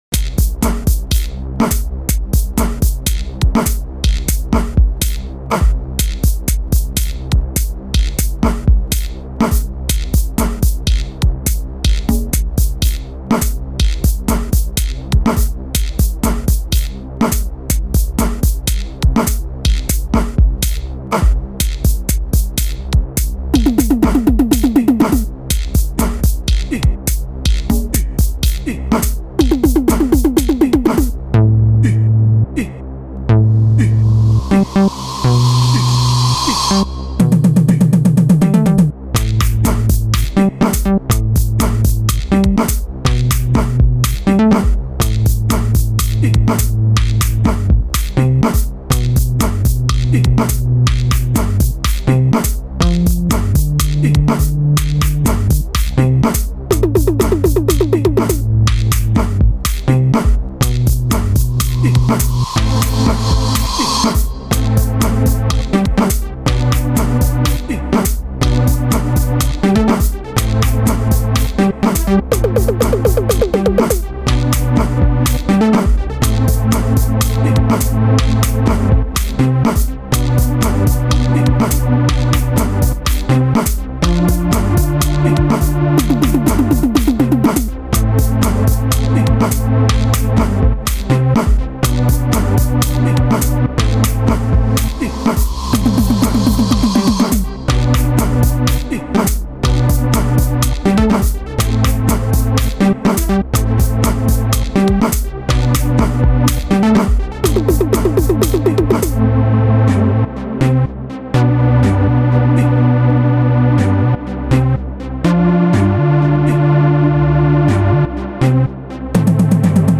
05:23 Genre : Gqom Size